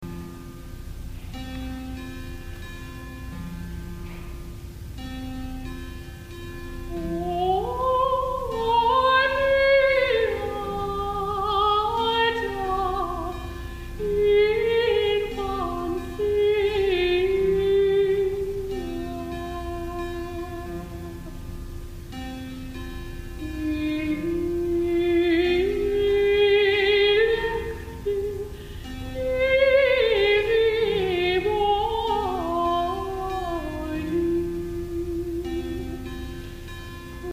Texts and Translations of Hildegard von Bingen's Gregorian Chants
freeclips.o_beata_live.mp3